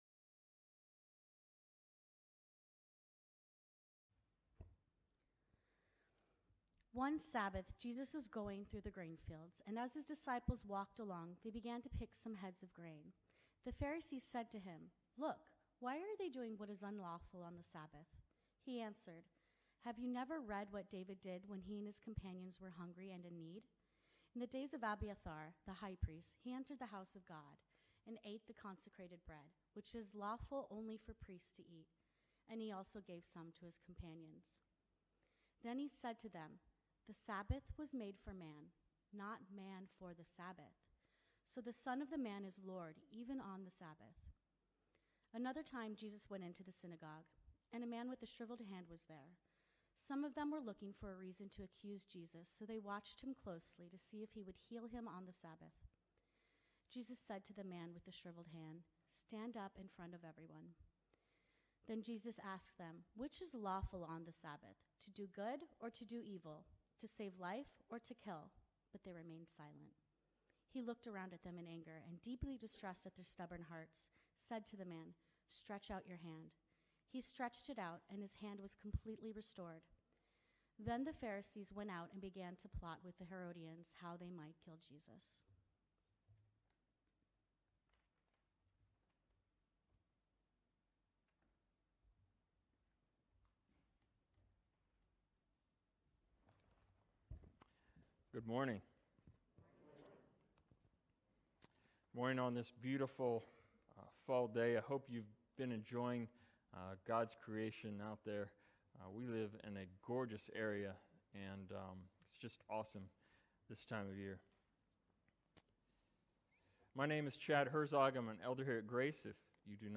Grace Sermons | Grace Evangelical Free Church